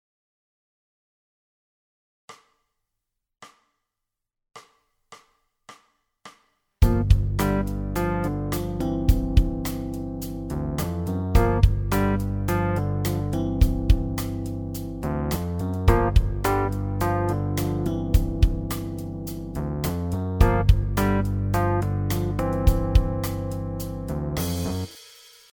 Funk `n` Soul Riff 7